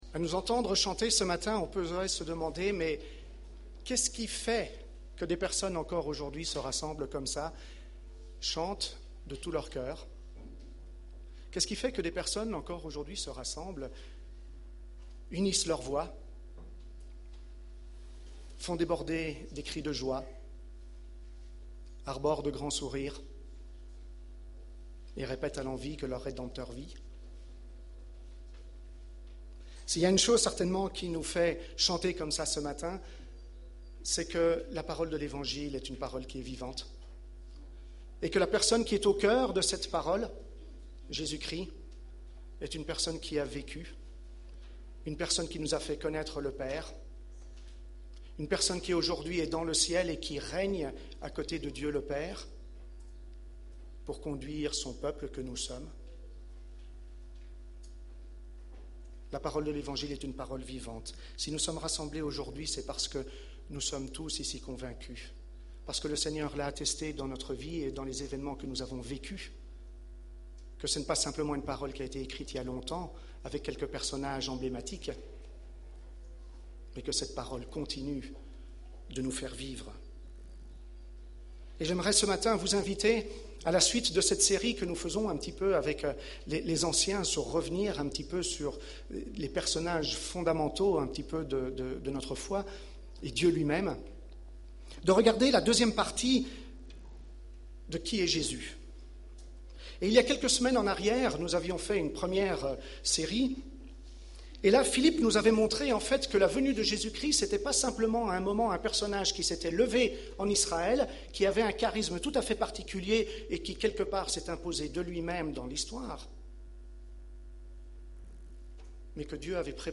Preacher: Conseil d'anciens | Series: